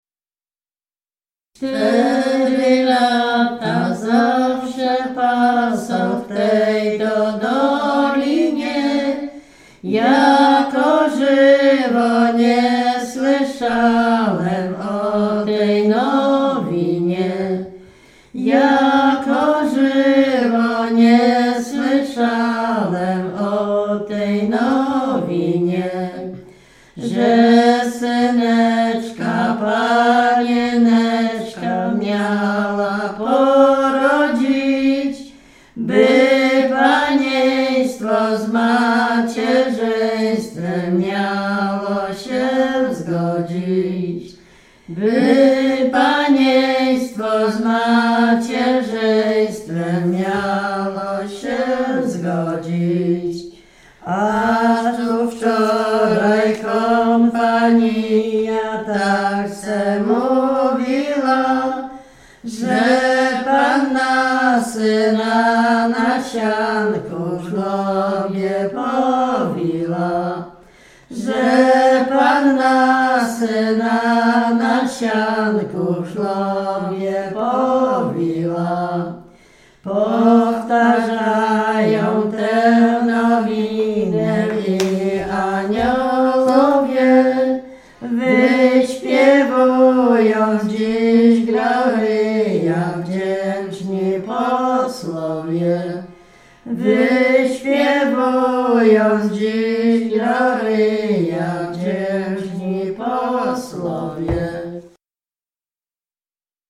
Śpiewaczki z Nowej Wsi Lubińskiej
Dolny Śląsk, powiat polkowicki, gmina Polkowice, wieś Nowa Wieś Lubińska
Śpiewaczki przesiedlone po 1945 r ze wsi Tuligłowy na Kresach na Dolny Śląsk
Kolęda